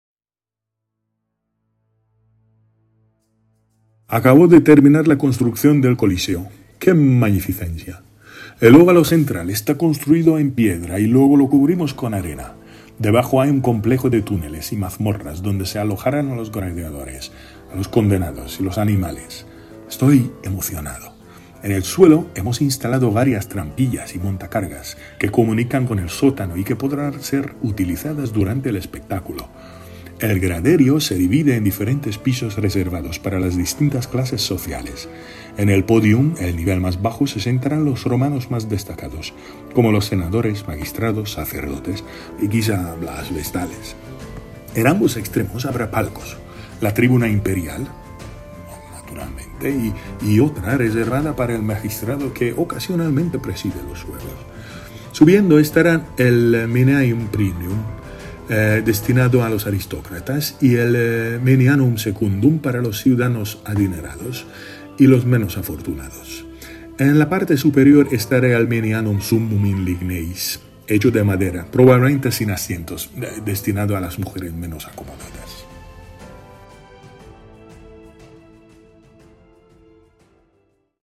Emperador Tito habla del Coliseo y su estructura